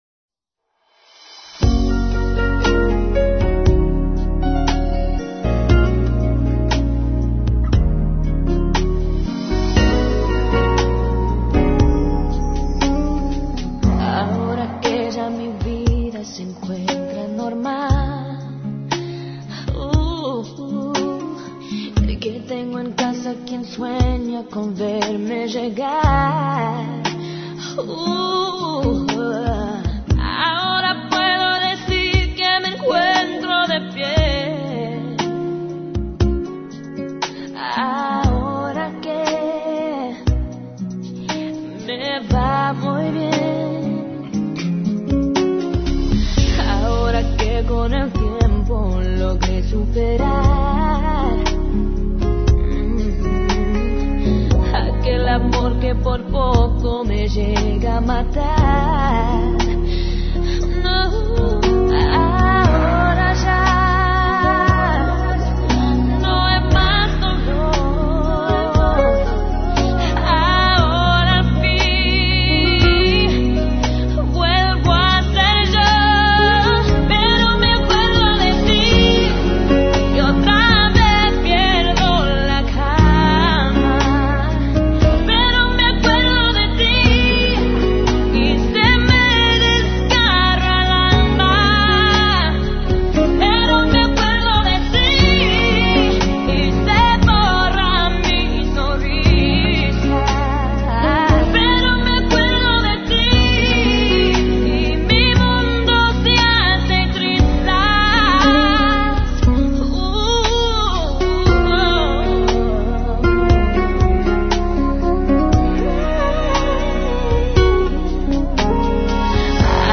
她用她迷人的嗓音诠释R&B歌曲，征服了无数人的耳朵。